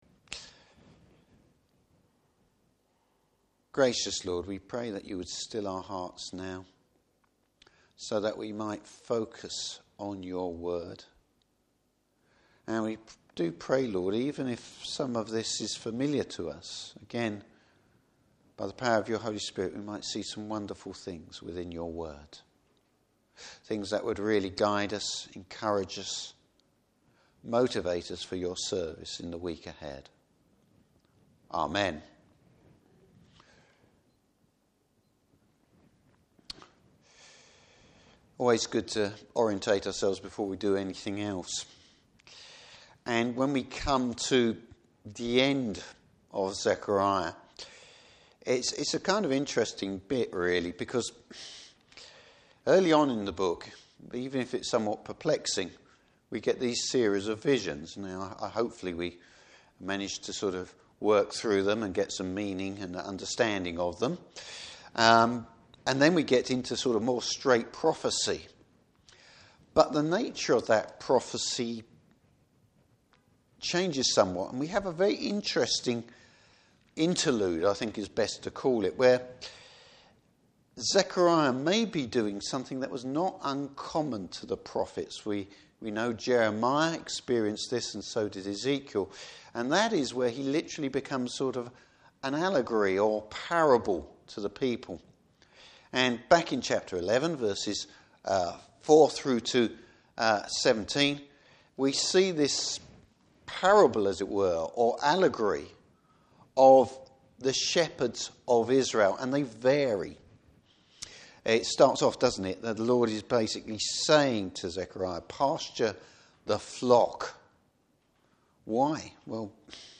Service Type: Evening Service The Lord refines his people.